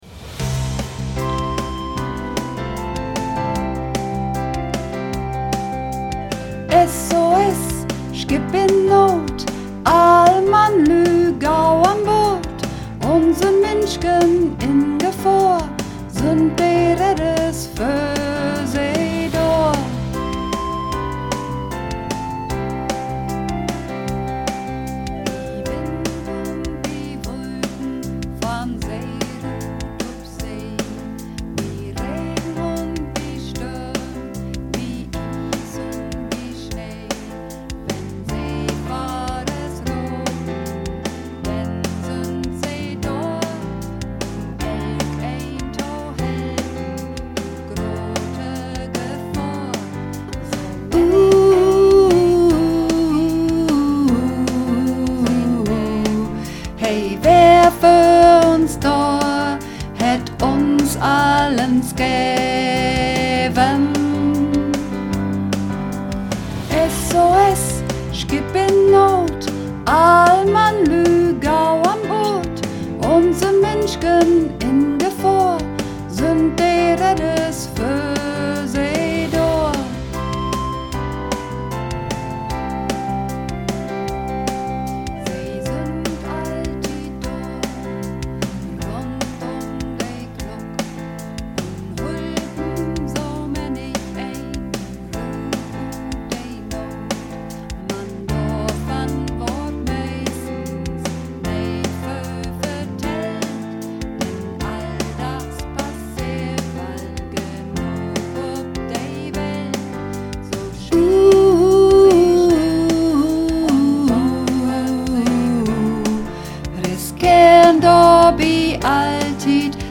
Übungsaufnahmen - SOS
Runterladen (Mit rechter Maustaste anklicken, Menübefehl auswählen)   SOS (Sopran)
SOS__2_Sopran.mp3